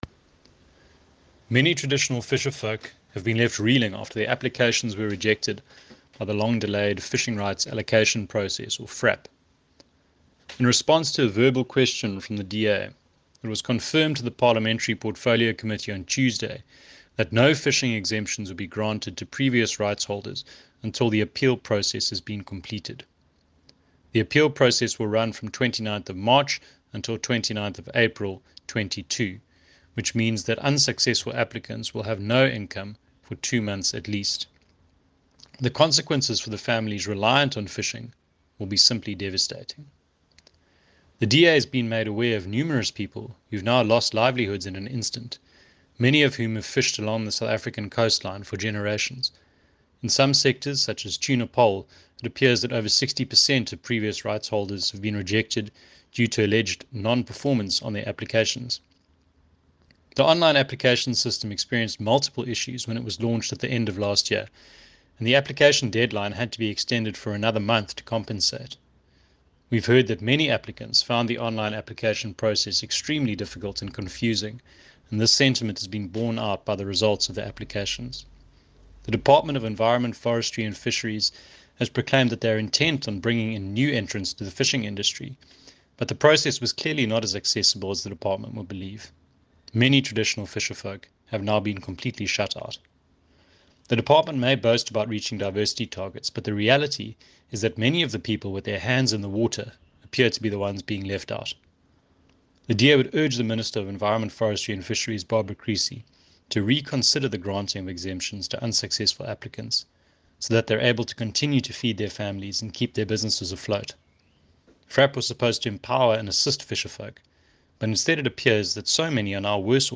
soundbite by Dave Bryant MP.